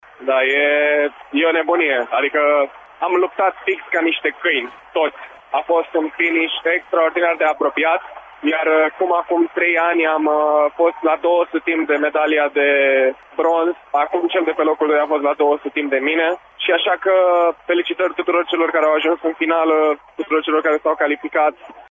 ”O nebunie!” a exclamat David Popovici la declarațiile de după festivitatea de premiere. Noul campion olimpic a vorbit, reporterilor Radio România, cu o sinceritate extraordinară despre sentimentele trăite: